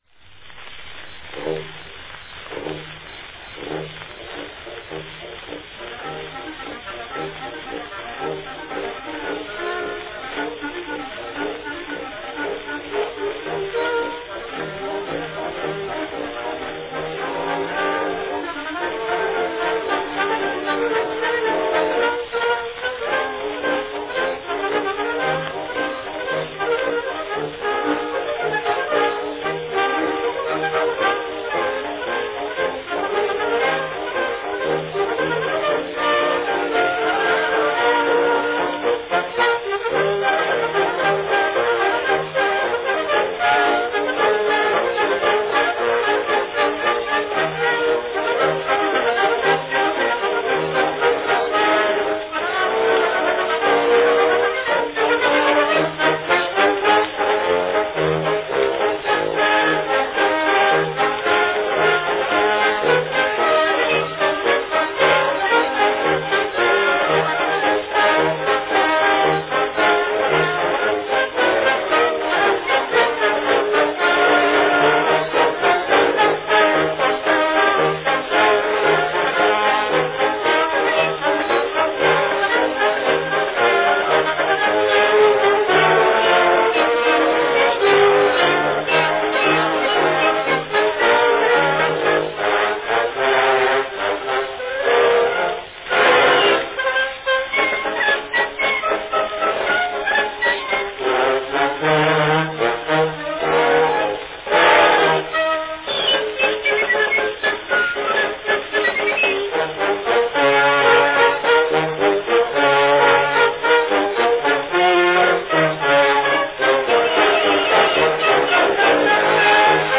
presented on an early long-play wax cylinder format
Category Band
Announcement None
Enjoy this well-recorded rendition